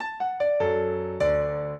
minuet0-8.wav